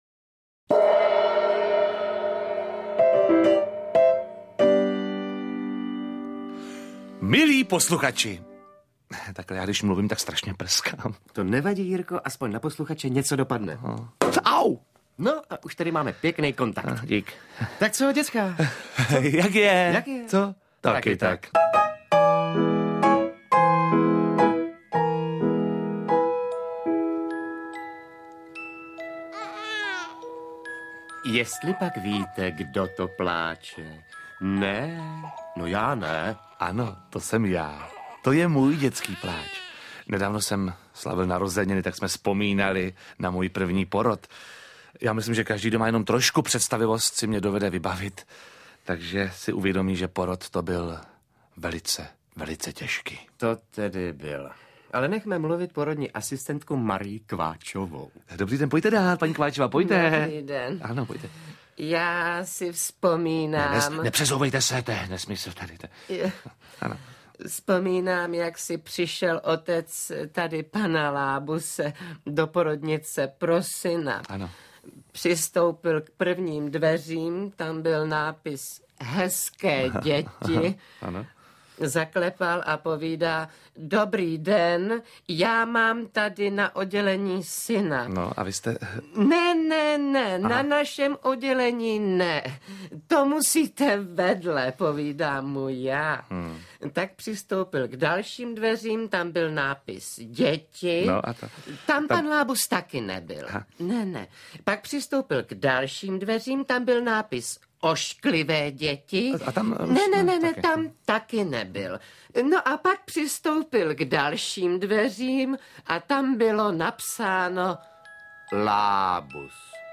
Lůďo, to je nap(r)osto no(r)mální a další povedené scénky - Oldřich Kaiser, Jiří Lábus - Audiokniha
• Čte: Oldřich Kaiser, Jiří Lábus